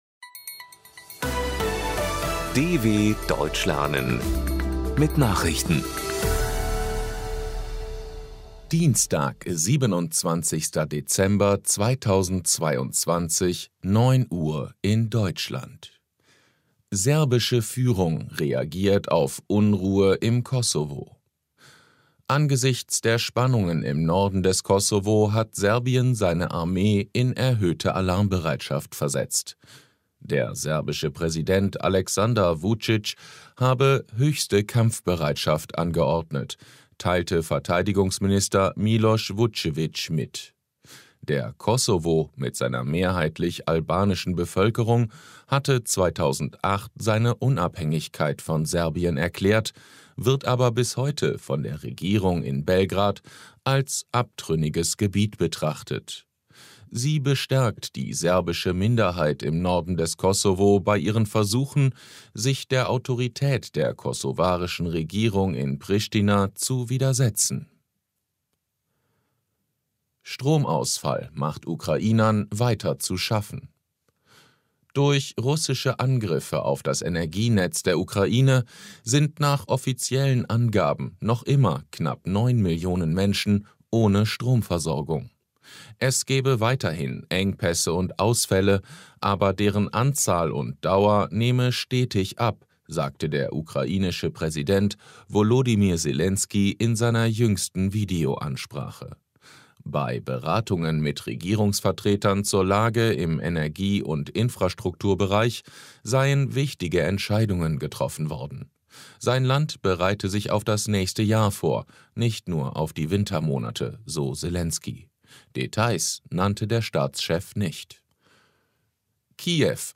27.12.2022 – Langsam gesprochene Nachrichten
Trainiere dein Hörverstehen mit den Nachrichten der Deutschen Welle von Dienstag – als Text und als verständlich gesprochene Audio-Datei.